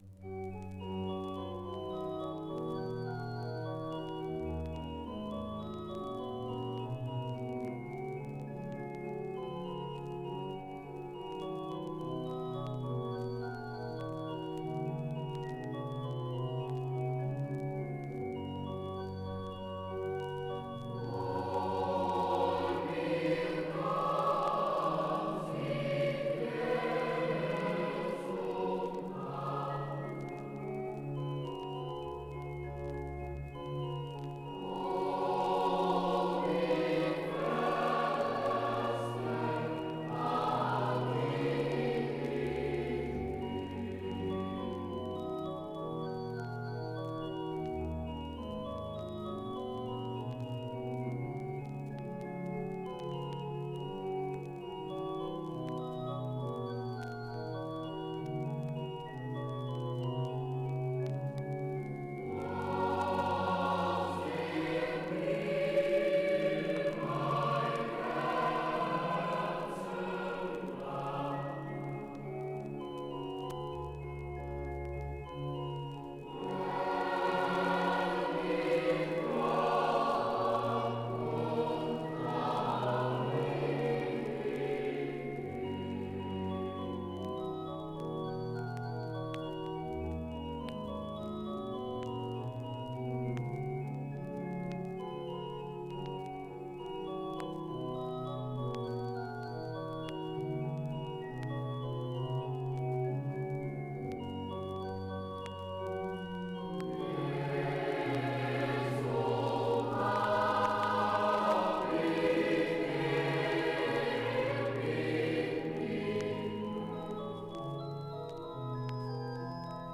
Deze website wordt opgebouwd uit het archief van het voormalige vermaarde Jongenskoor Cantasona uit Boxtel.